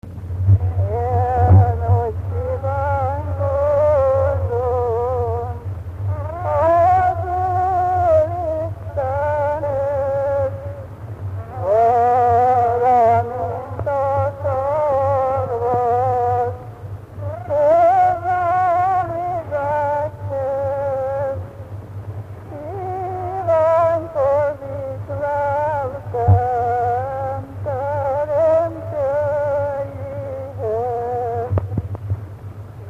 Dunántúl - Sopron vm. - Vitnyéd
Dallamtípus: Lóbúcsúztató - halottas 2; Hol vagy, én szerelmes Jézus Krisztusom
Stílus: 8. Újszerű kisambitusú dallamok